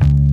808s
A#_07_Bass_04_SP.wav